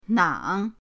nang3.mp3